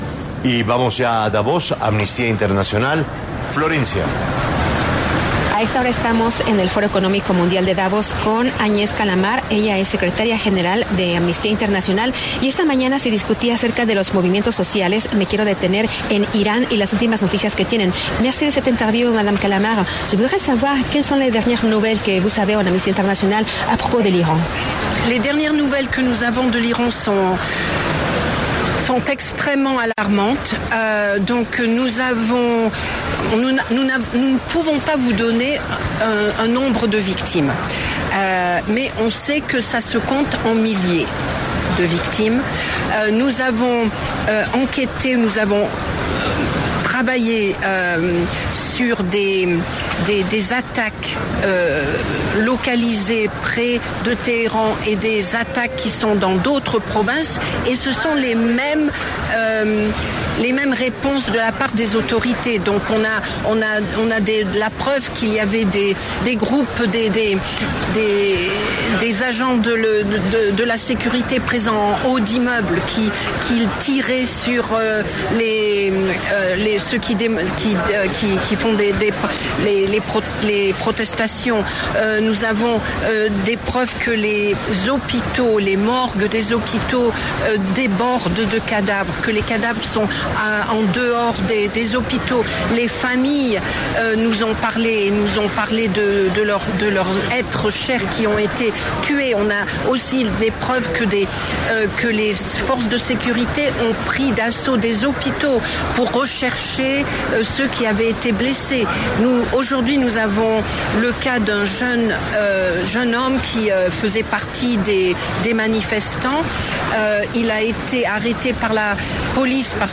Agnes Callamard, secretaria general de Amnistía Internacional y experta en derechos humanos, habló con 6AM W, con Julio Sánchez Cristo, a propósito de la tensa situación social que se vive en Irán con la represión hacia los manifestantes que deja cientos de heridos y muertos.